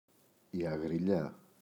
αγριλιά, η [aγriꞋʎa]